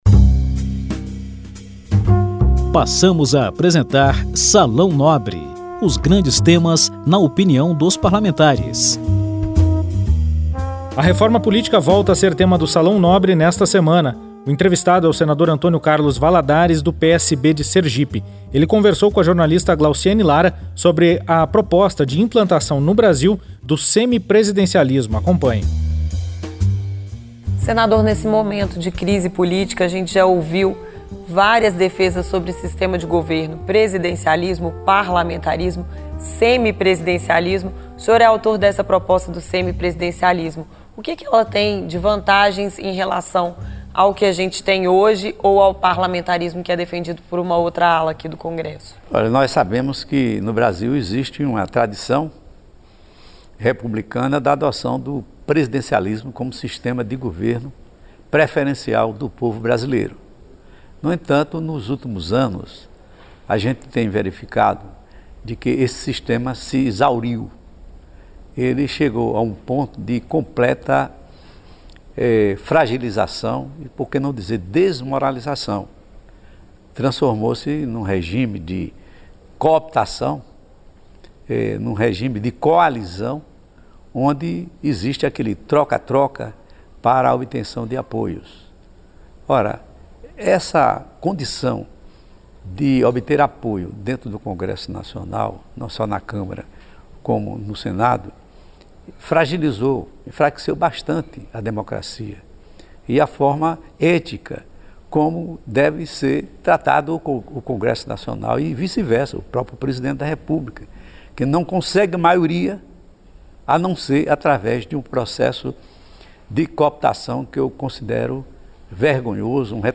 Programa de entrevistas com temas de relevância nacional na opinião de líderes partidários, presidentes das comissões, autores e relatores de projetos.